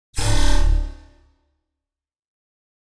دانلود صدای اعلان خطر 10 از ساعد نیوز با لینک مستقیم و کیفیت بالا
جلوه های صوتی